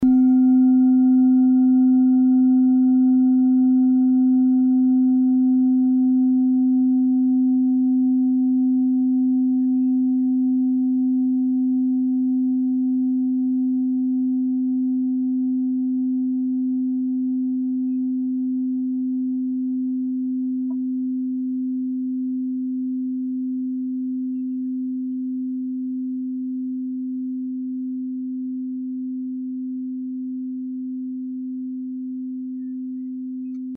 Klangschale Bengalen Nr.1
Klangschale-Durchmesser: 16,4cm
Sie ist neu und wurde gezielt nach altem 7-Metalle-Rezept in Handarbeit gezogen und gehämmert.
(Ermittelt mit dem Filzklöppel oder Gummikernschlegel)